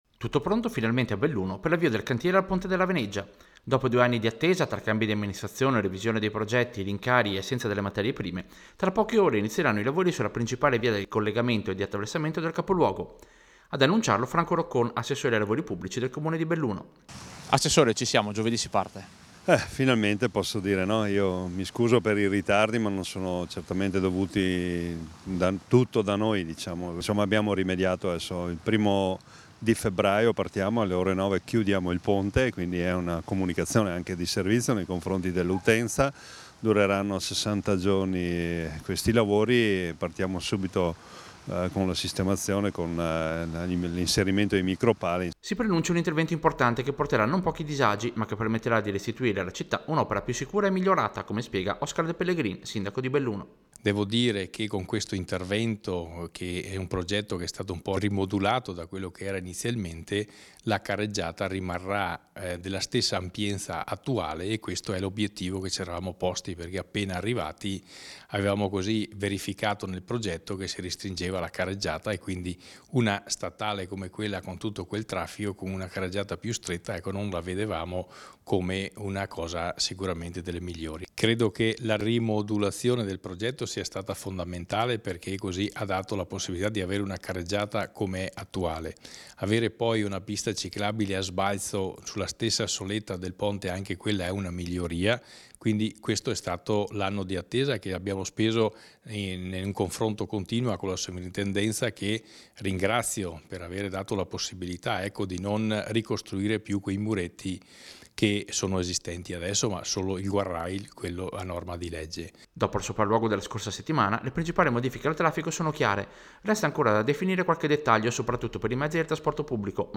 Radio-Piu-Servizio-Avvio-cantiere-Veneggia.mp3